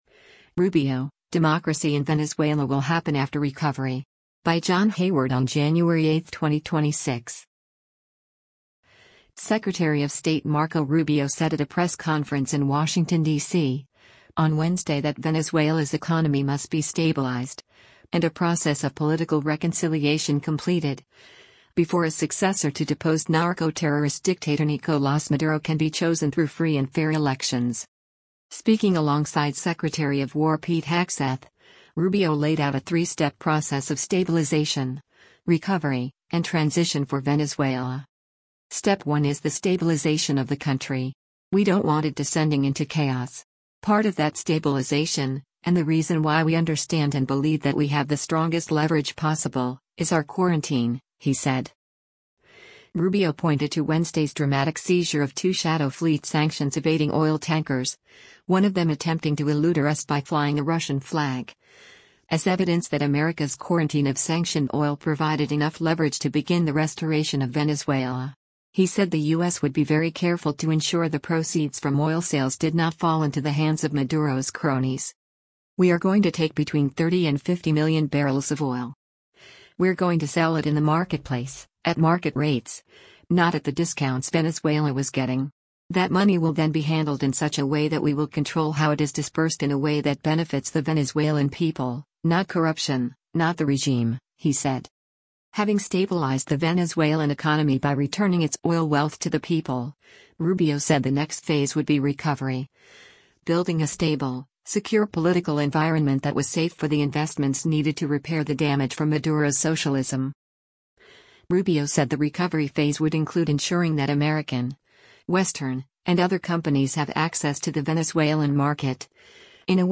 Secretary of State Marco Rubio said at a press conference in Washington, DC, on Wednesday that Venezuela’s economy must be stabilized, and a process of political reconciliation completed, before a successor to deposed narco-terrorist dictator Nicolás Maduro can be chosen through free and fair elections.
Speaking alongside Secretary of War Pete Hegseth, Rubio laid out a three-step process of “stabilization, recovery, and transition” for Venezuela.